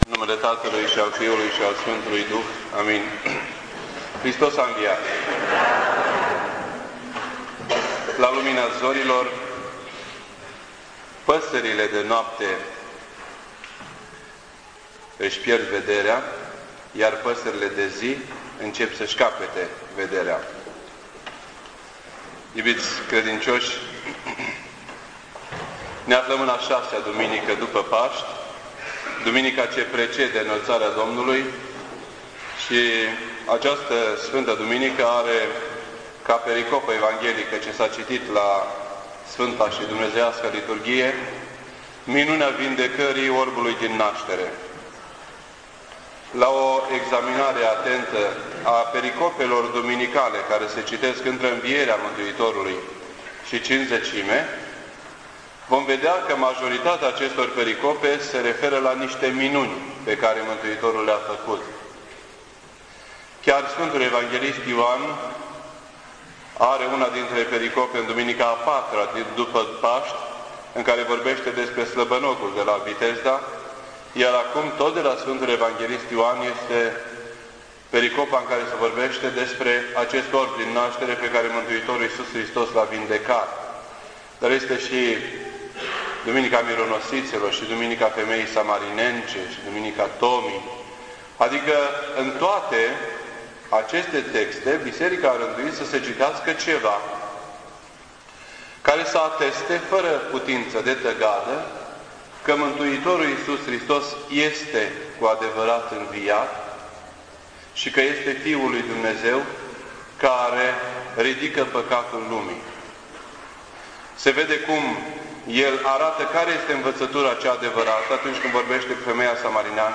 This entry was posted on Sunday, May 13th, 2007 at 10:17 AM and is filed under Predici ortodoxe in format audio.